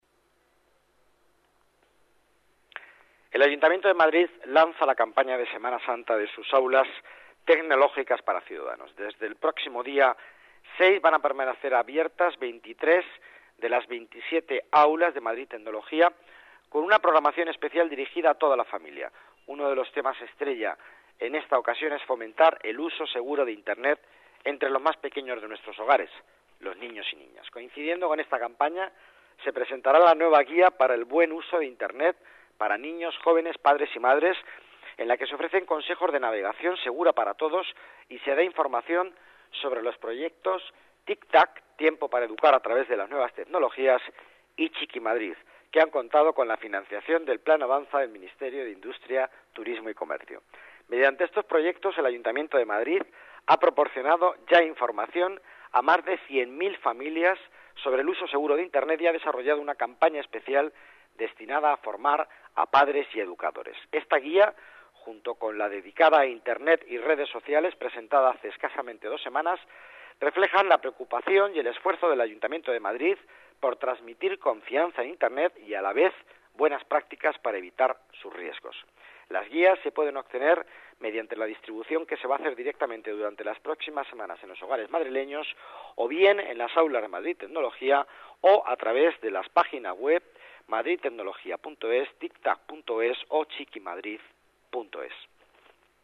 Nueva ventana:Declaraciones del delegado de Economía, Miguel Ángel Villanueva: Buen uso de Internet